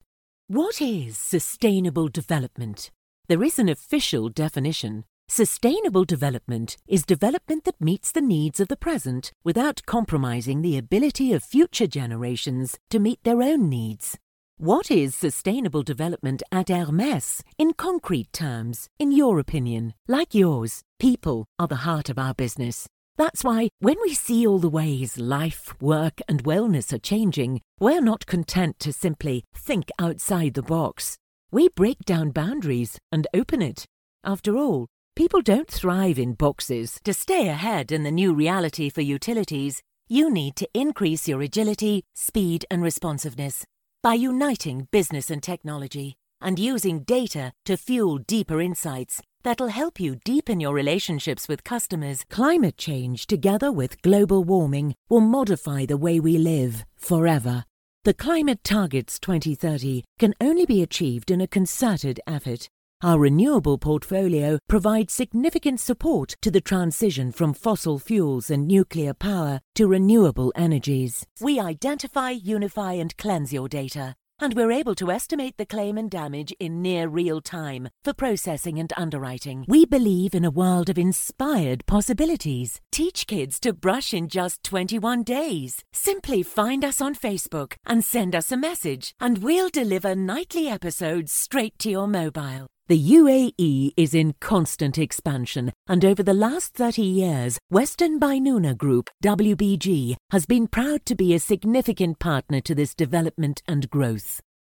Hire Professional Female Voice Over Talent
English (South African)
Adult (30-50) | Older Sound (50+)
0313Corporate_III.mp3